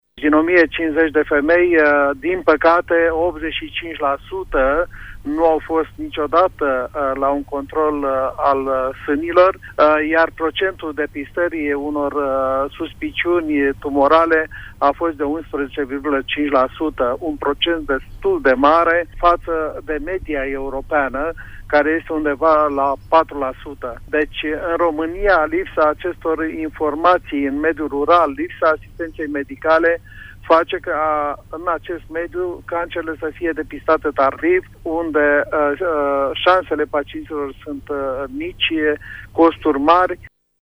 în emisiunea Pulsul Zilei de la RTM